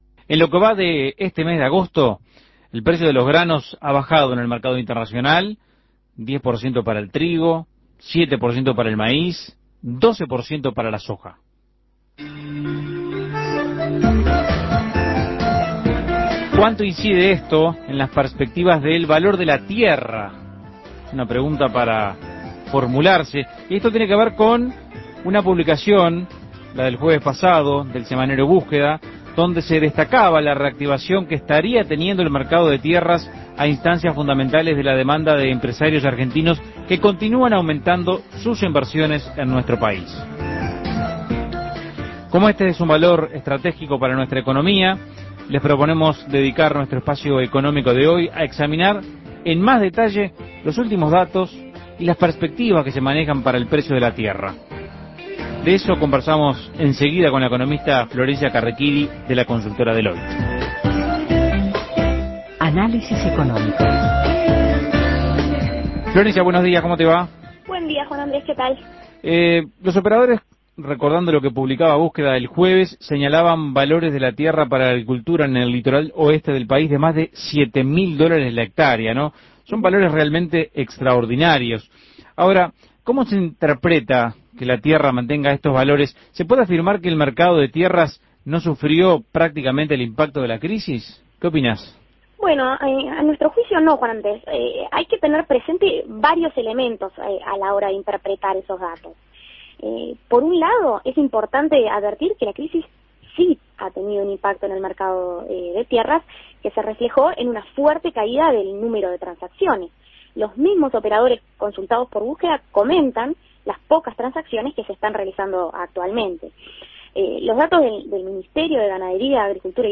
Análisis Económico El precio de la tierra en Uruguay